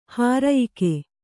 ♪ hārayike